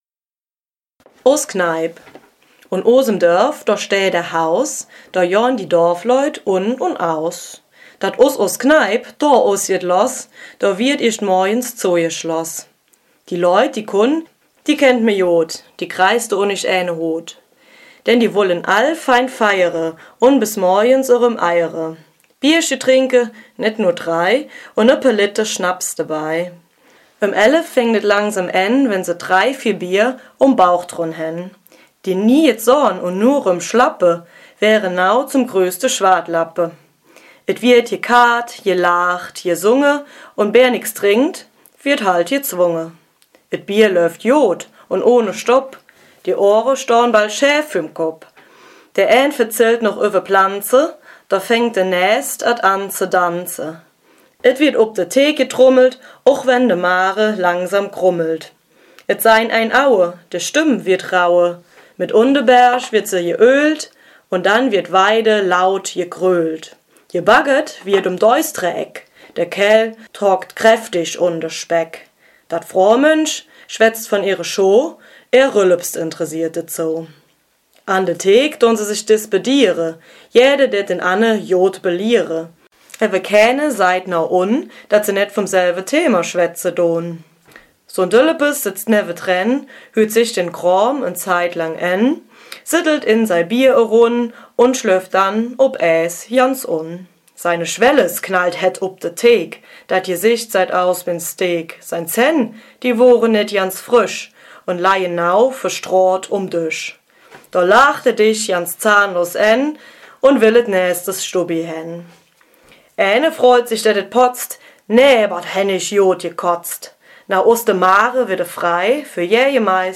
Letzendlich wird der/die  jeweilige Autor-/in ihre Verzellschje/Stöötsche selber vorlesen, um dann eine Audio CD zu erstellen.
Die 24 Audiodateien sind auf eine einheitliche Lautstärke gebracht worden.